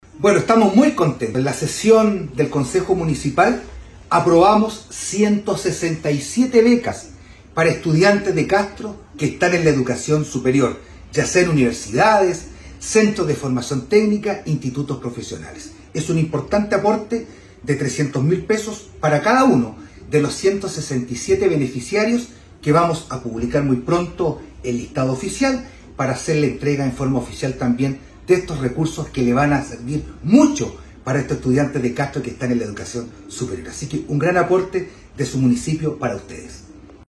ALCALDE-VERA-BECAS.mp3